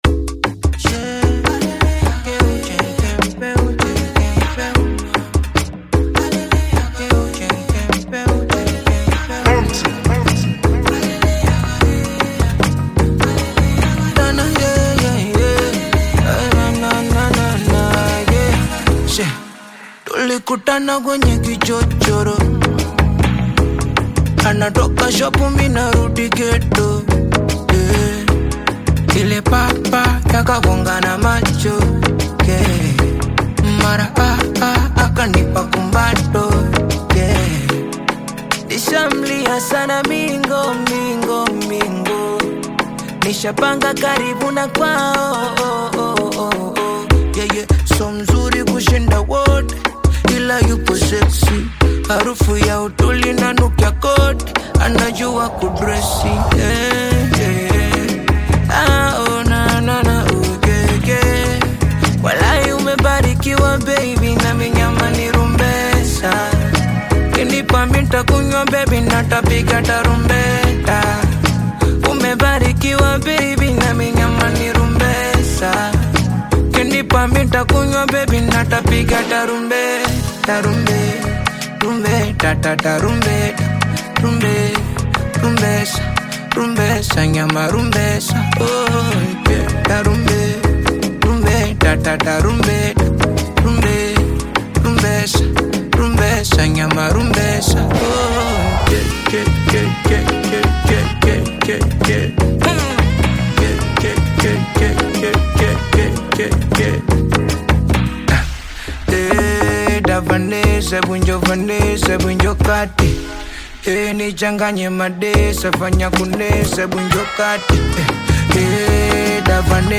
Tanzanian Bongo Flava artist, singer and songwriter